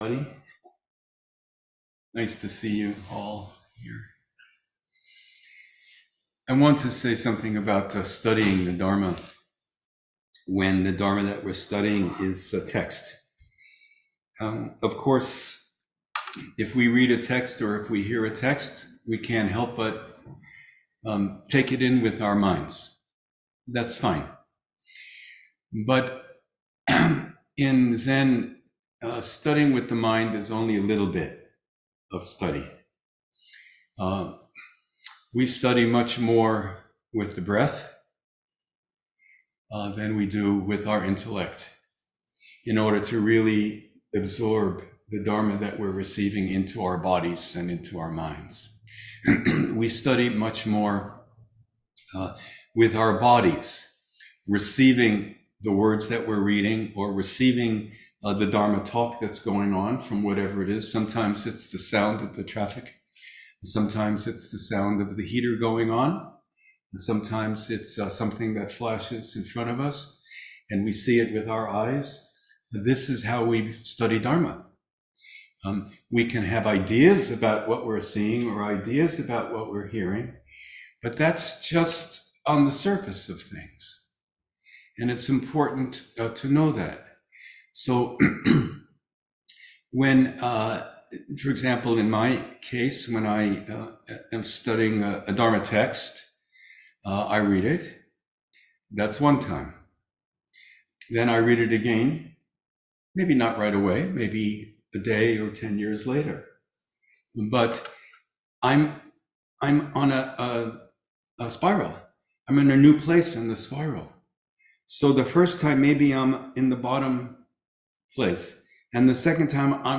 INFORMAL REFLECTIONS AFTER WEDNESDAY ZAZEN
If the teaching you’ve selected is on the quiet side, you may like to use ear buds or head phones.